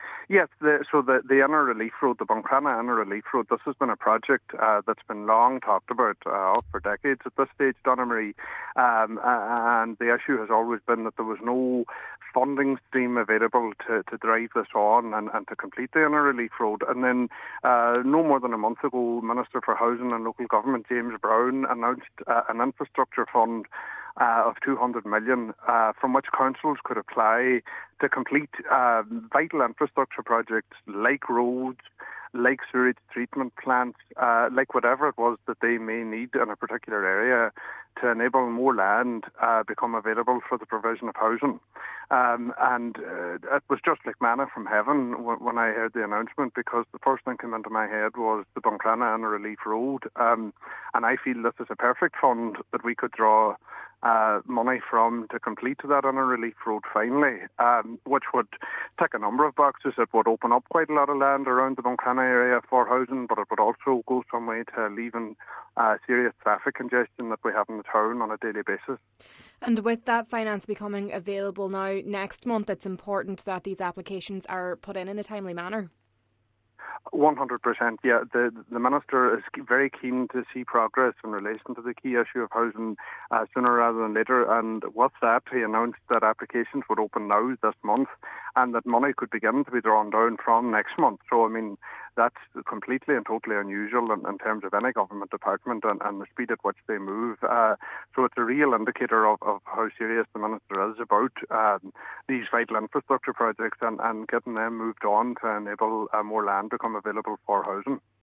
The road remains unfinished due to a lack of funding, and with grants being issued next month, Cllr Bradley says an application must be submitted immediately: